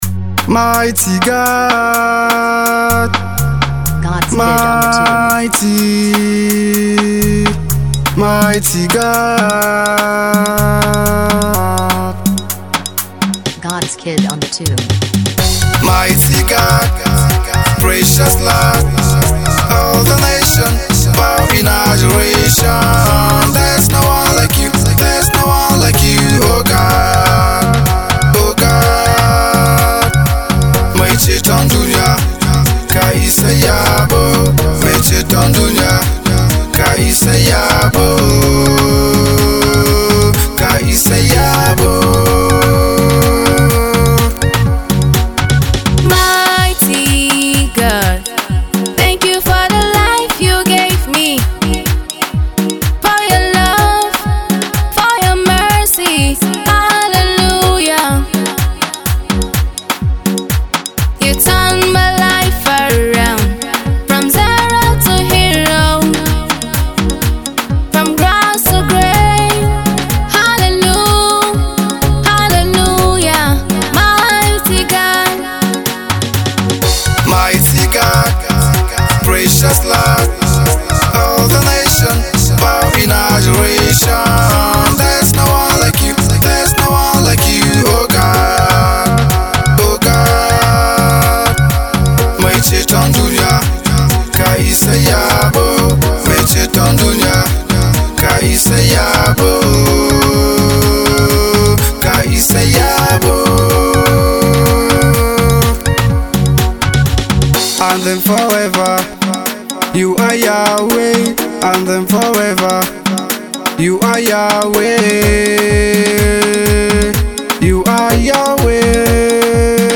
This soul-stirring and inspirational song
a powerful anthem of worship and adoration